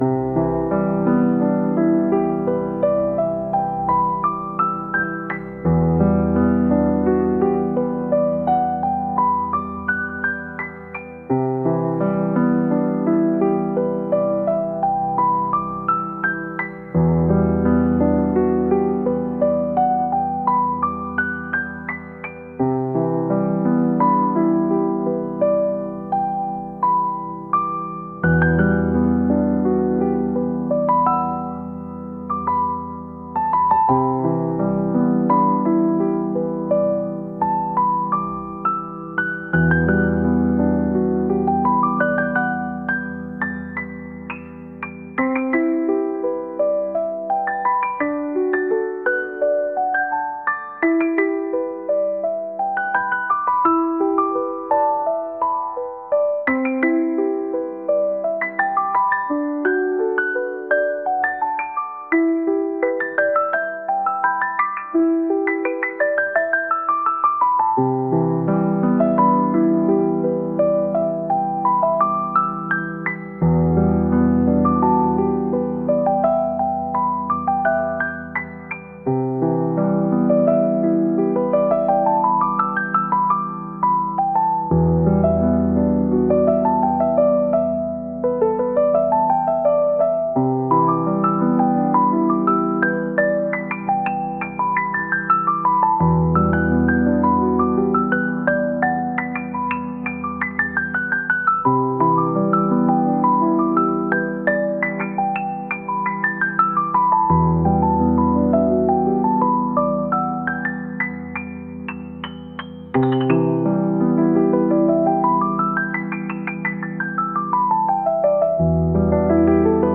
ゆったりとしたピアノ曲です 音楽素材（MP3）ファイルのダウンロード、ご利用の前に必ず下記項目をご確認ください。